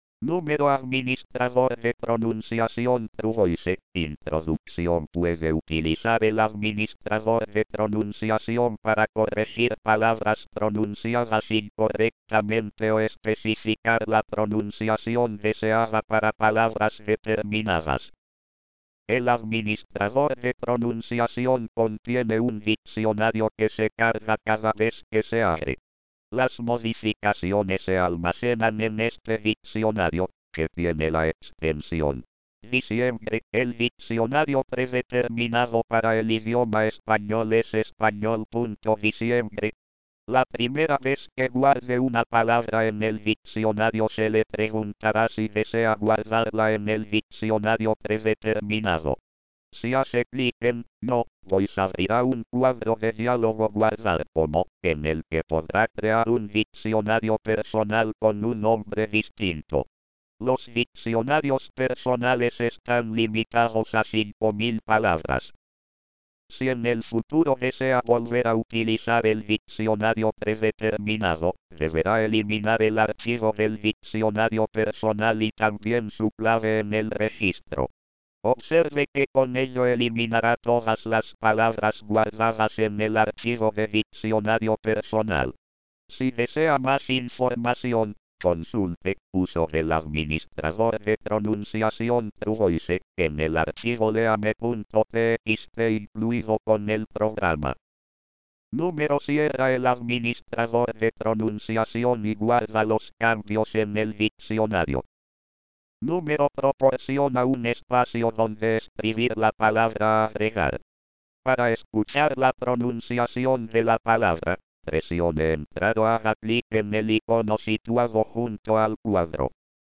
truvoice spanish.mp3